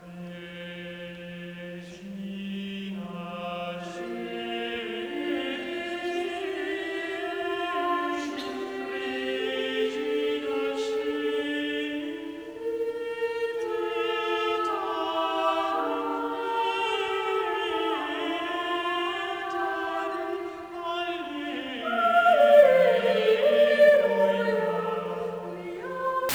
"Regina caeli", two-part motet from Bicinia seu duarum vocum cantiones aliquot sacrae, Cologne, Gerhard Grevenbruch, 1593.
De Castro's version for two voices integrates the plain chant melody of Regina caeli in a lively counterpoint.